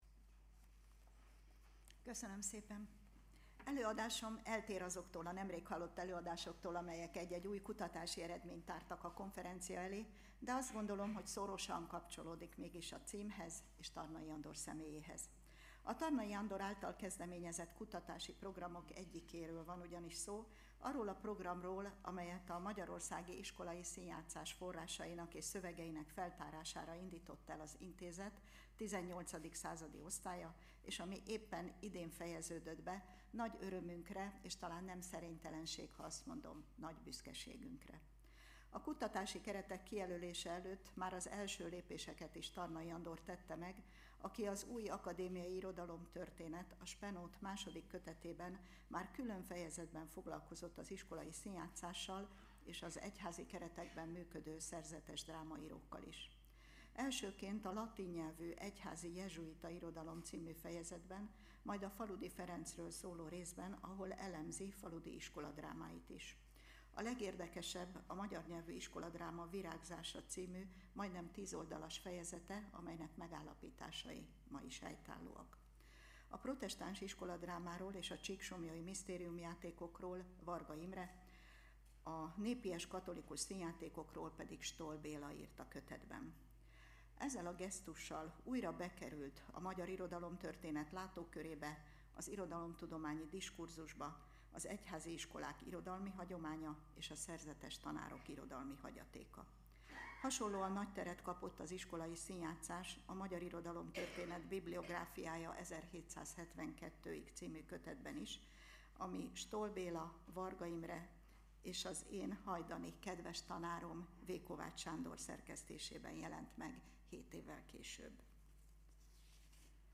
lecturer